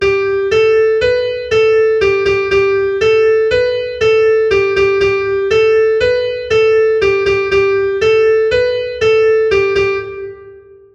It's not the same as the riff.